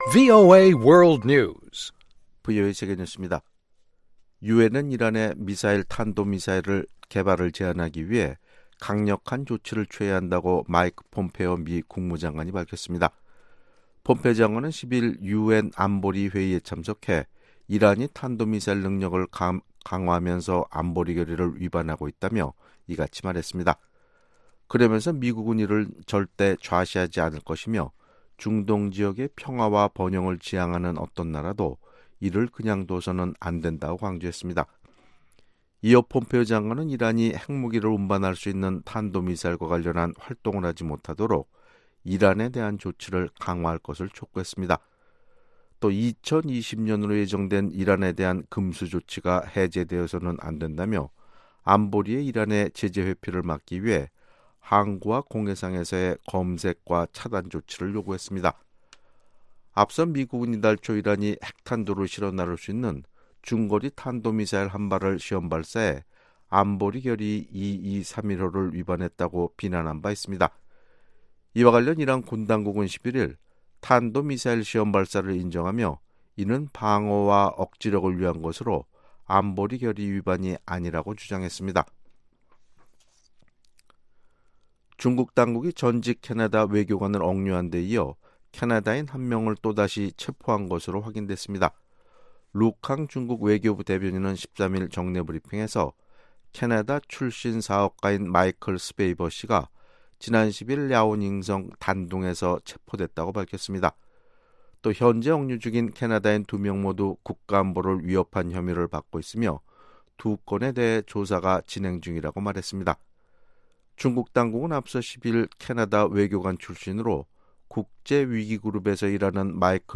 VOA 한국어 아침 뉴스 프로그램 '워싱턴 뉴스 광장' 2018년 12월 14일 방송입니다. 미 국무부는 북한에 특정지원을 금지한 대통령 결정문은 인신매매 퇴치 의지를 반영하는 것이라고 밝혔습니다. 렉스 틸러슨 전 미 국무장관은 북한 문제 해결에는 시간이 필요하다며, 이를 위해 동맹은 물론 중국과 러시아의 협조를 유지하는 것이 중요하다고 말했습니다.